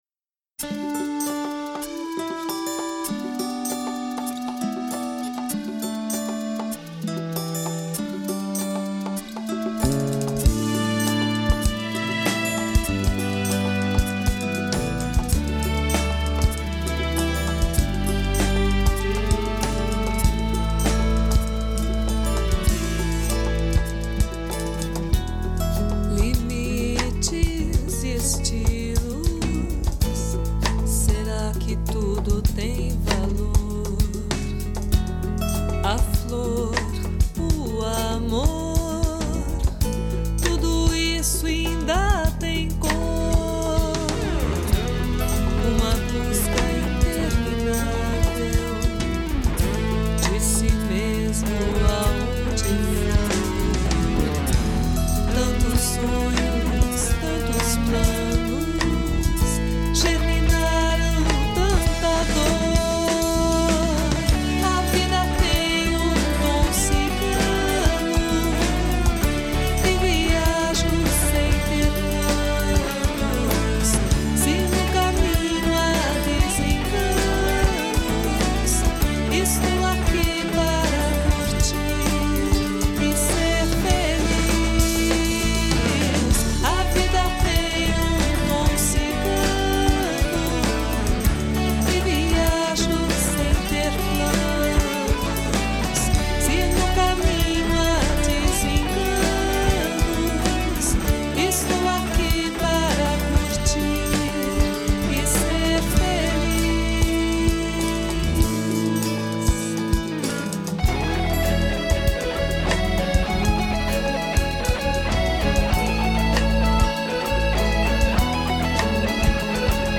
com vocais precisos e cheios de personalidade.